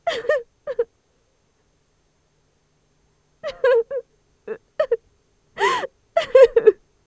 Tiếng Khóc Con Gái
Tiếng con người 377 lượt xem 03/03/2026
Tải tiếng con gái khóc mp3, download hiệu ứng âm thanh tiếng khóc của con gái mp3, tải tiếng cô gái trẻ khóc mp3, âm thanh tiếng khóc phụ nữ về điện thoại, máy tính...